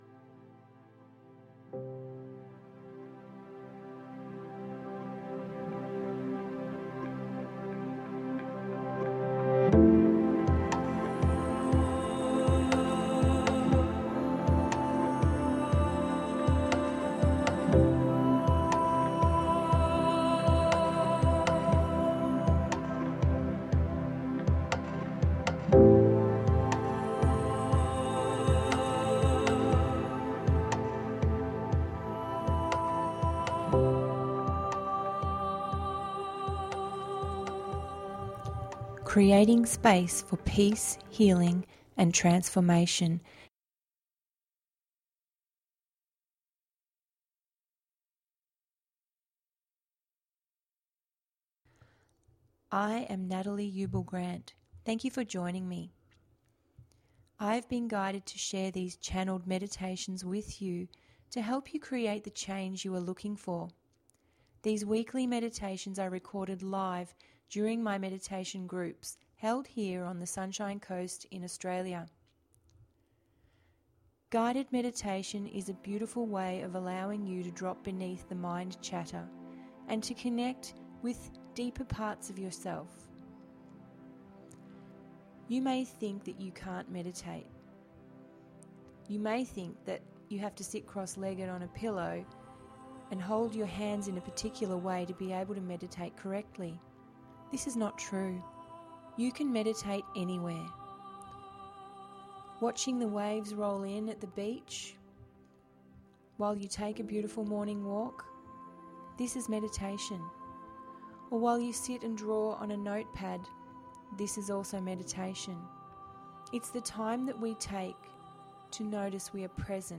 Meditation duration approx. 20 mins